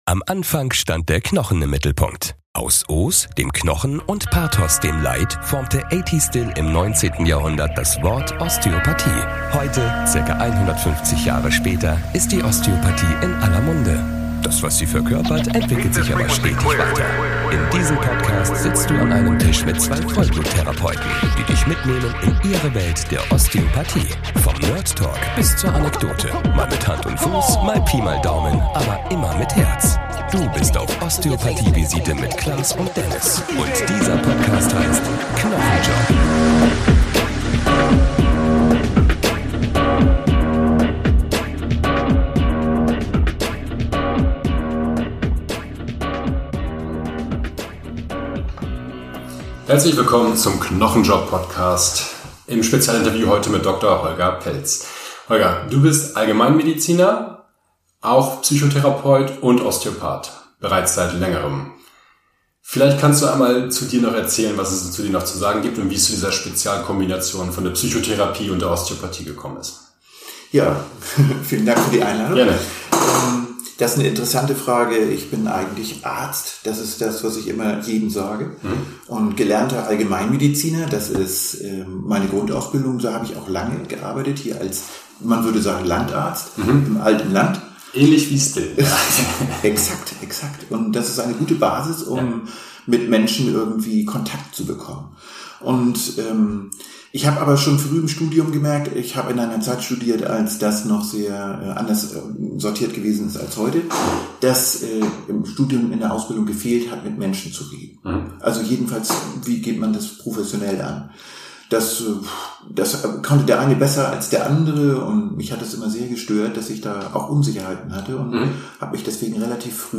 Interview-Spezial